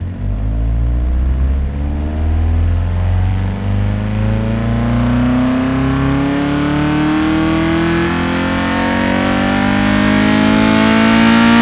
rr3-assets/files/.depot/audio/Vehicles/i4_02/i4_02_accel.wav
i4_02_accel.wav